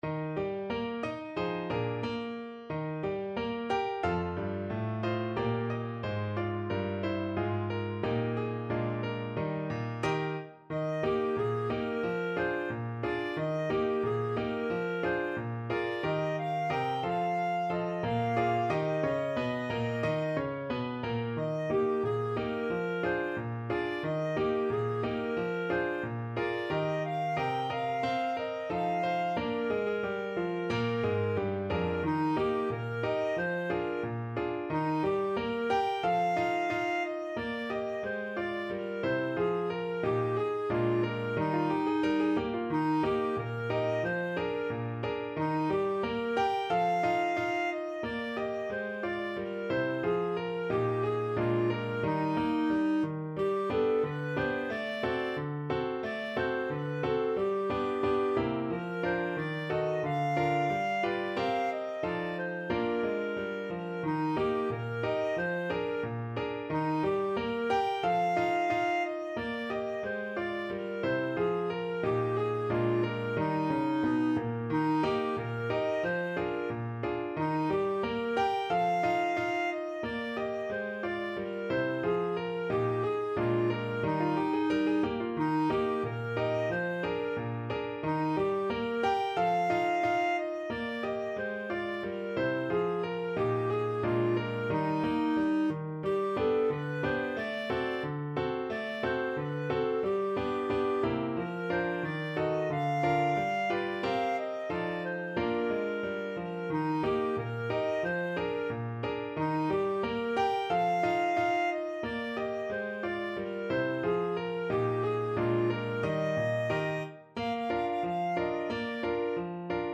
2/2 (View more 2/2 Music)
=90 Fast and cheerful
Clarinet  (View more Easy Clarinet Music)
Pop (View more Pop Clarinet Music)